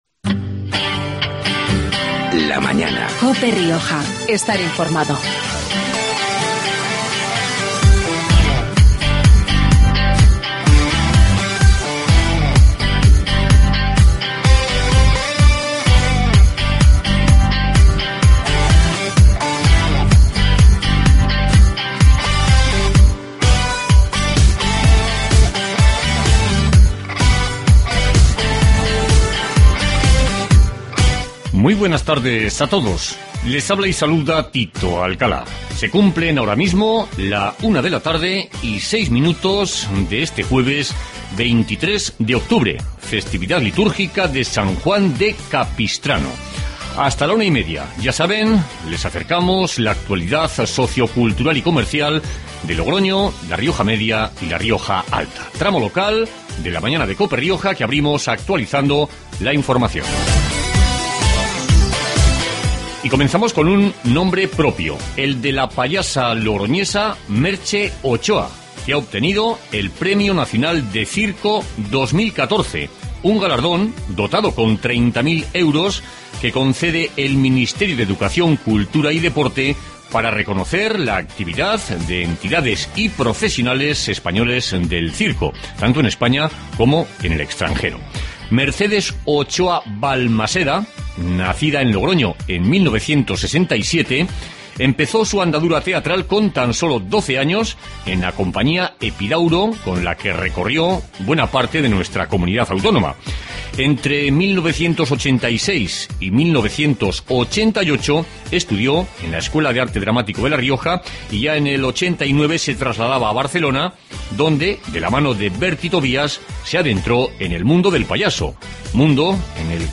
Magazine de actualidad de La Rioja